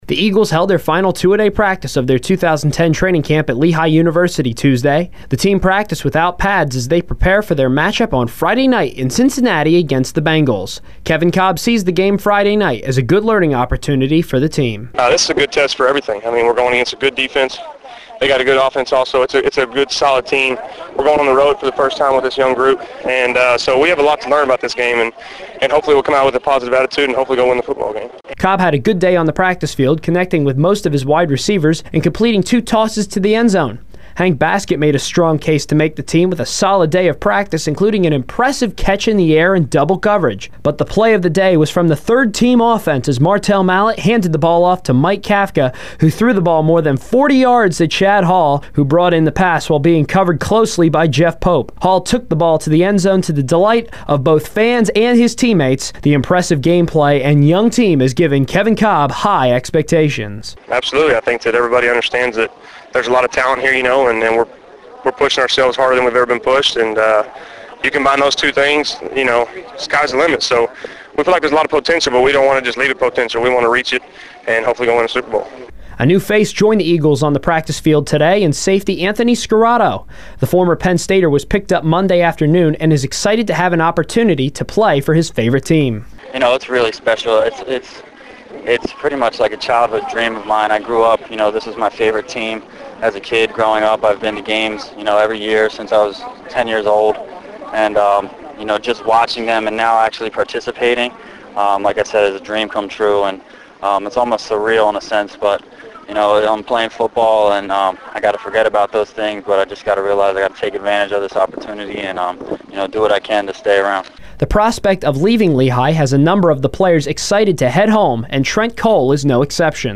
The reports included audio I gathered from interviews with both players and coaches.